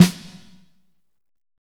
Index of /90_sSampleCDs/Northstar - Drumscapes Roland/SNR_Snares 2/SNR_P_C Snares x